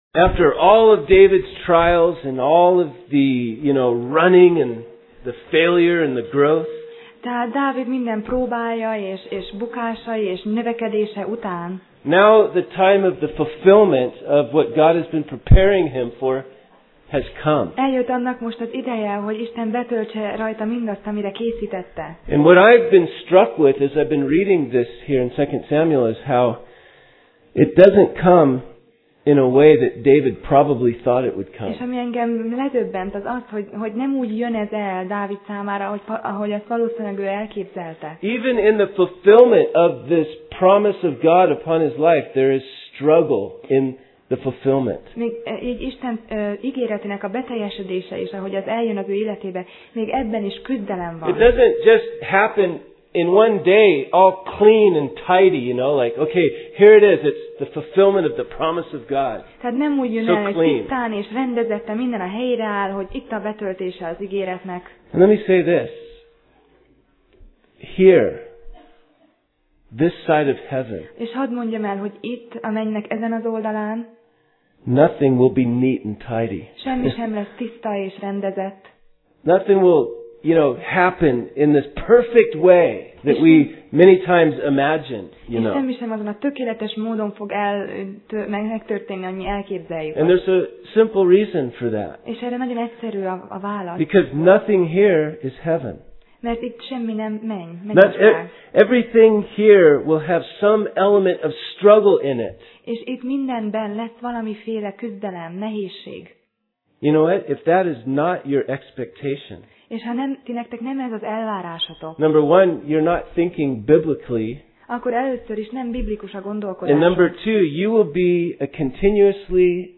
Sorozat: 2Sámuel Passage: 2Sámuel (2Samuel) 3:1-39 Alkalom: Szerda Este